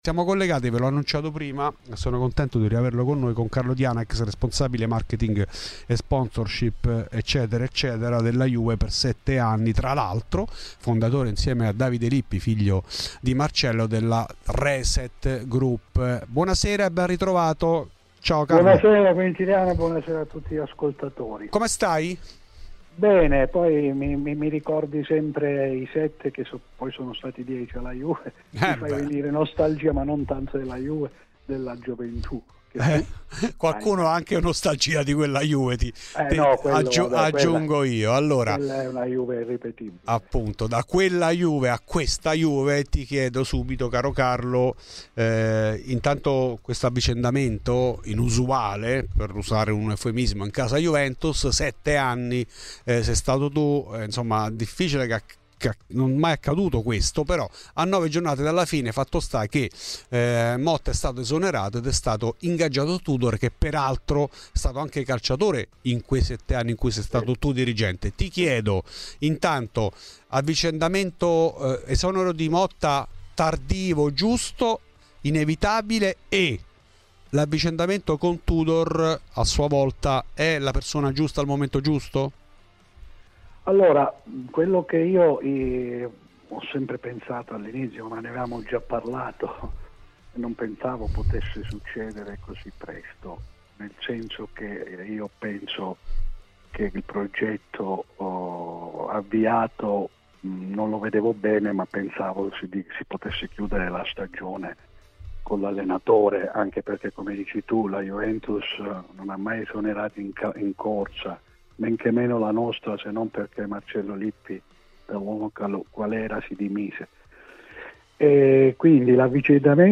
Puoi ascoltare l'intervista completa nel podcast